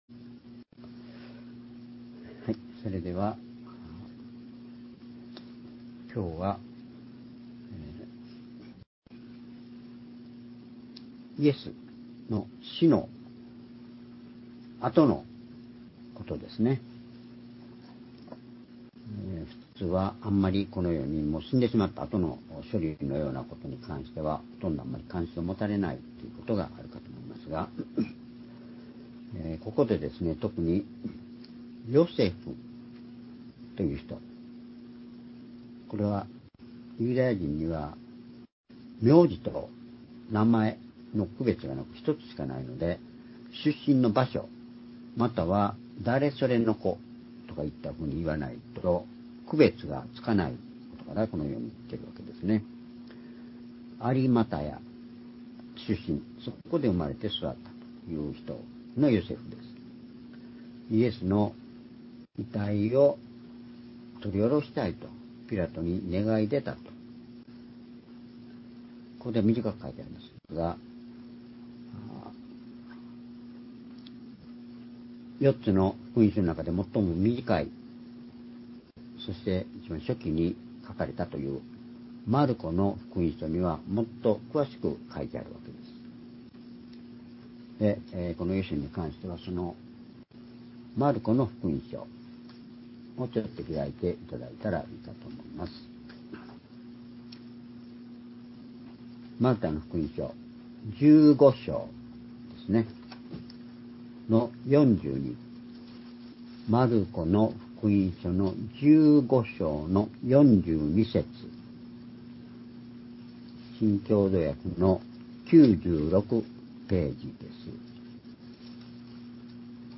主日礼拝日時 2025年3月2日(主日礼拝) 聖書講話箇所 「死してなお力あるイエス」 ヨハネ19章38～42節 ※視聴できない場合は をクリックしてください。